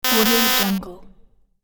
دانلود افکت صوتی زنگ جواب اشتباه 98
Wrong Answer Buzzer Notification 98 royalty free audio track is a great option for any project that requires game sounds and other aspects such as a video, game and app.